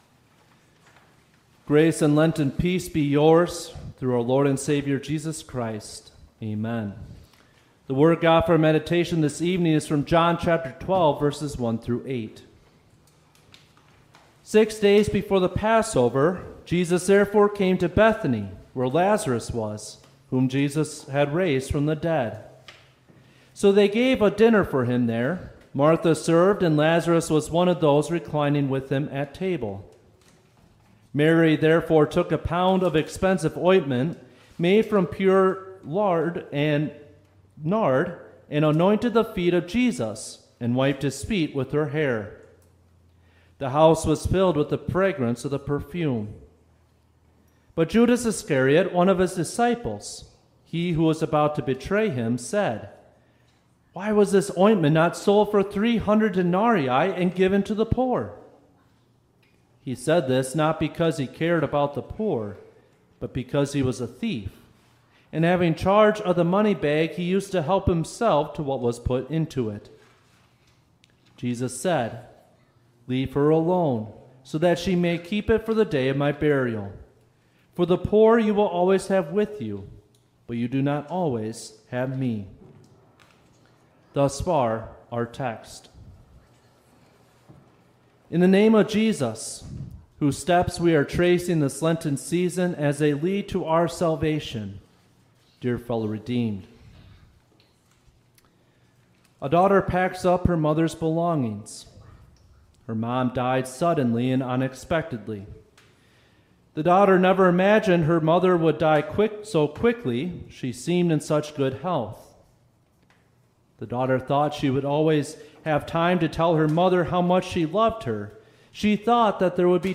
Midweek Lent 1